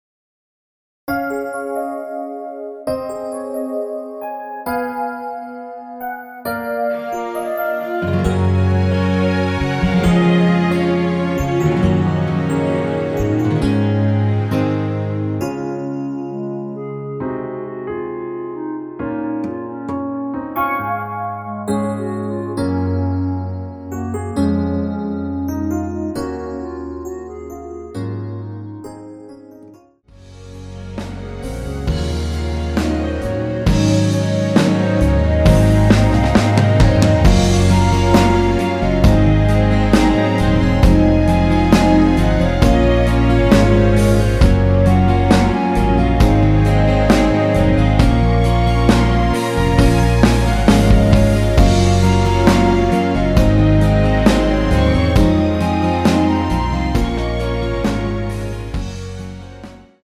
원키에서(-1)내린 멜로디 포함된 MR입니다.
Db
앞부분30초, 뒷부분30초씩 편집해서 올려 드리고 있습니다.
중간에 음이 끈어지고 다시 나오는 이유는